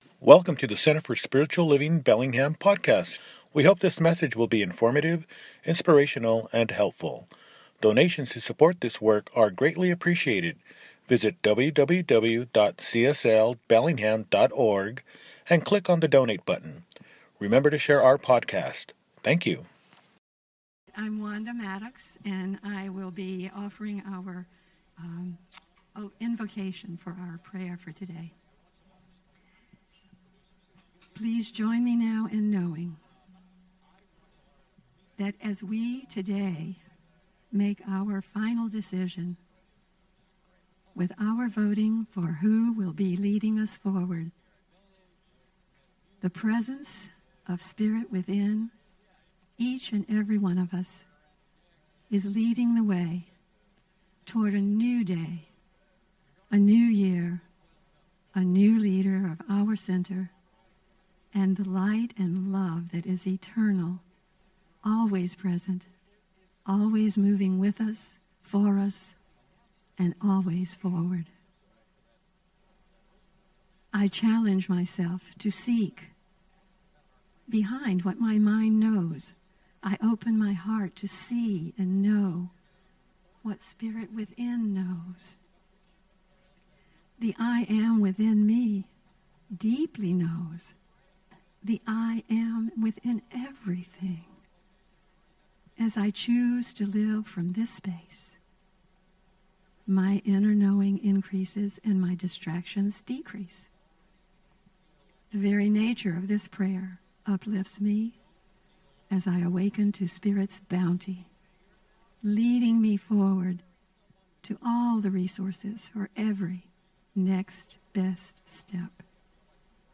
Oct 5, 2025 | Podcasts, Services